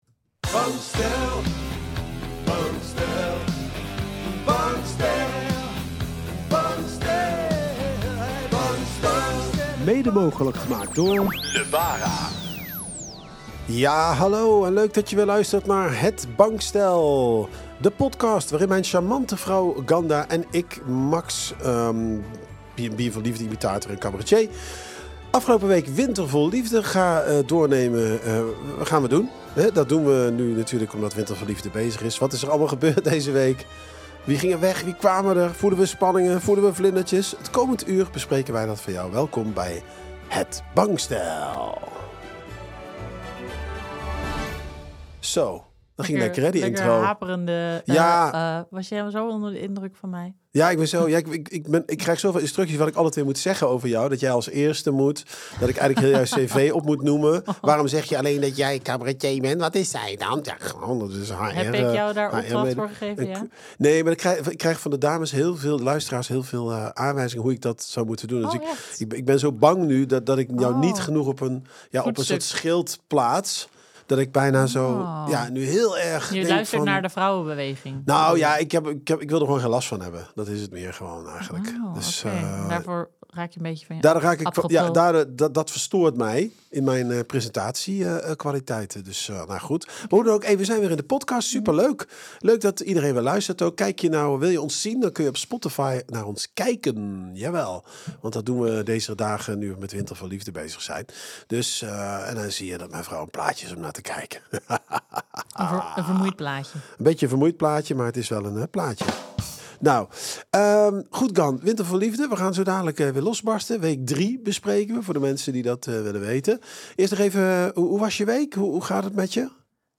Een uurtje filosoferen, analyseren, speculeren en natuurlijk imiteren, door Het Bankstel!